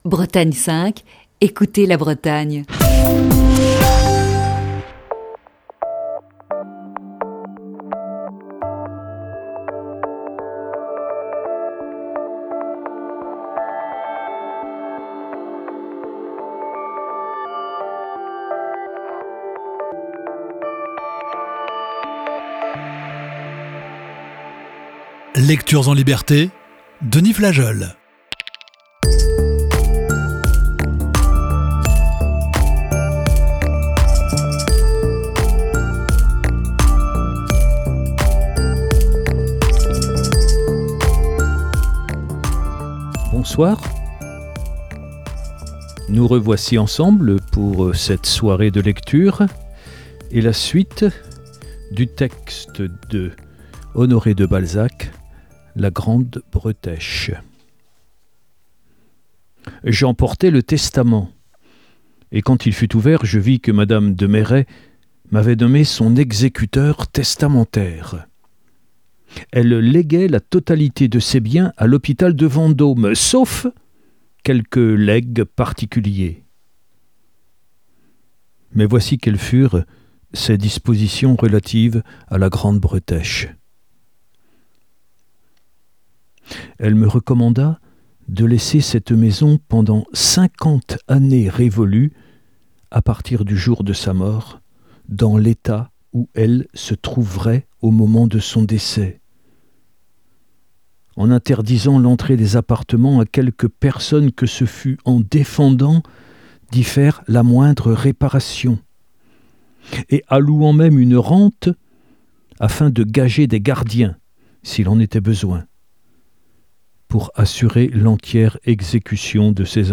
Émission du 1er décembre 2020.